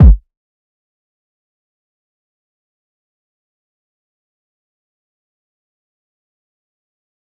DMV3_Kick 3.wav